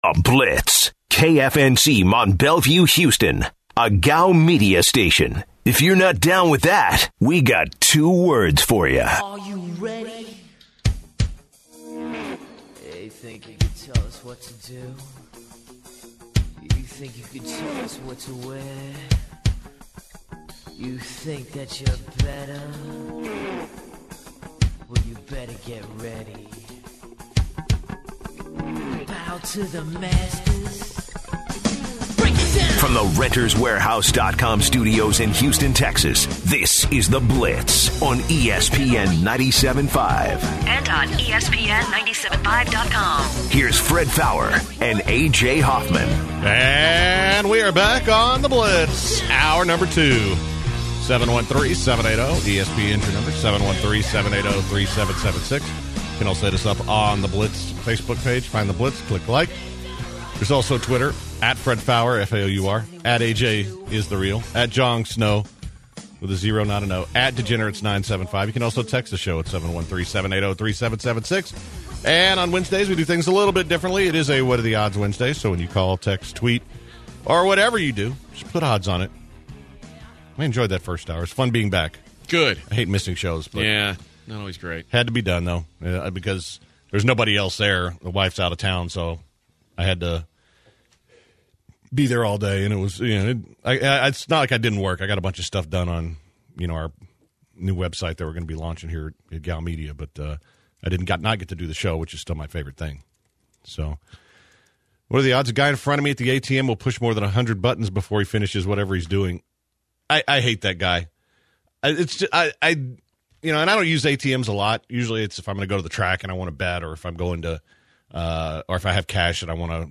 In hour 2, the crew discusses the viral photograph of a father mowing with a tornado in the background, takes questions from callers, and continues what are the odds.